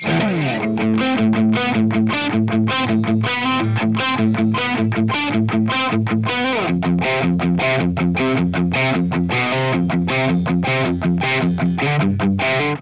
Metal Riff
RAW AUDIO CLIPS ONLY, NO POST-PROCESSING EFFECTS